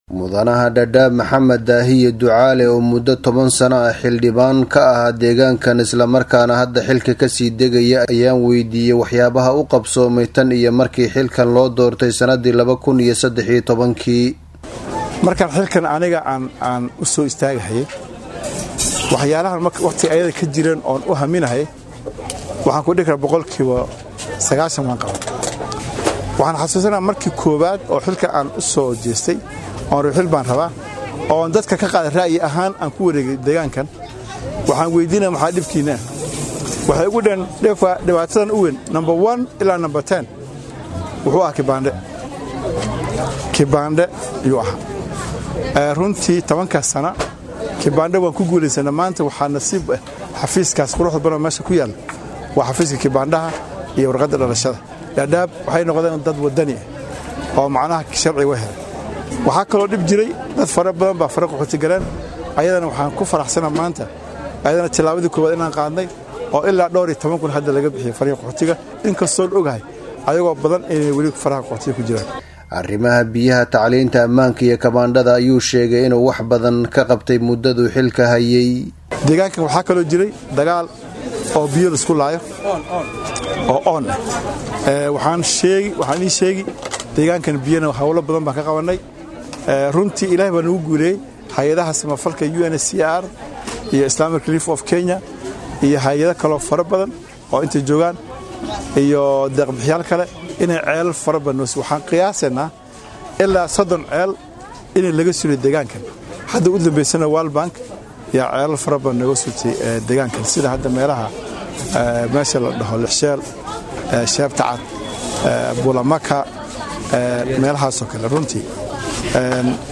DHAGEYSO:Xildhibaanka Dadaab oo ka hadlay waxyaabaha u qabsoomay ku dhawaad 10 sano